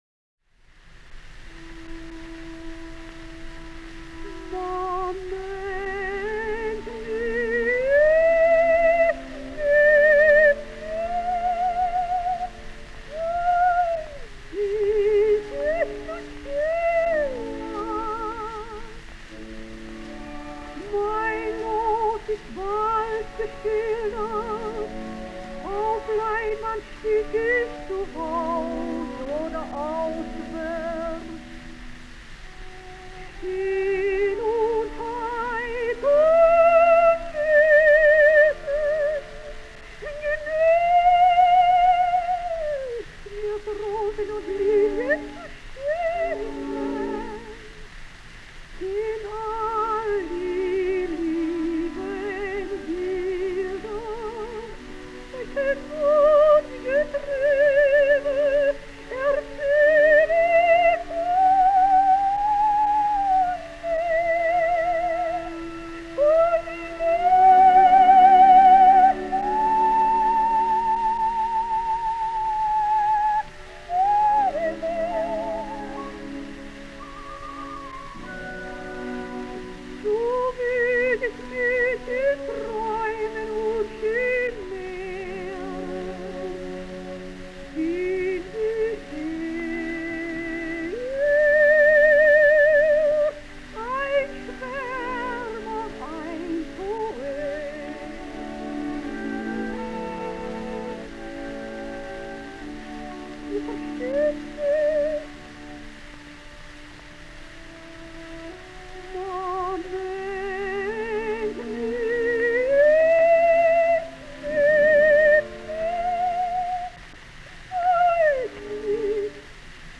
137 лет со дня рождения австрийской певицы (сопрано) Зельмы Курц (Selma Kurz)